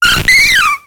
Cri de Joliflor dans Pokémon X et Y.